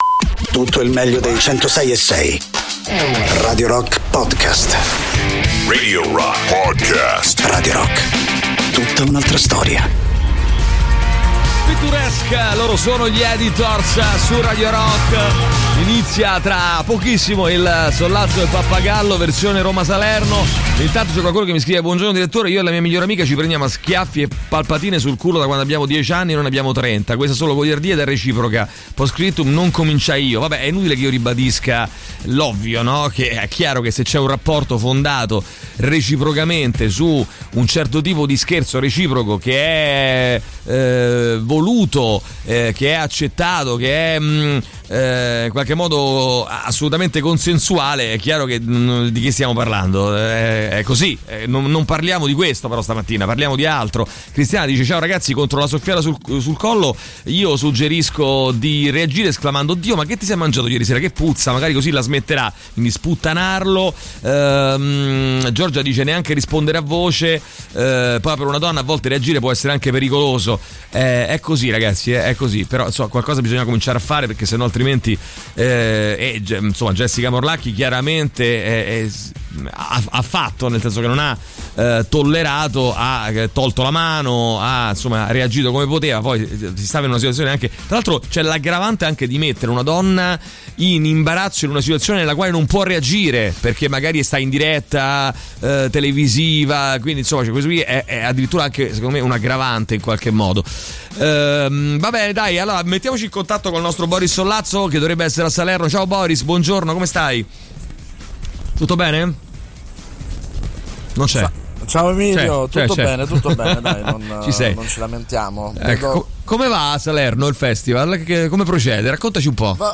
in collegamento telefonico da Salerno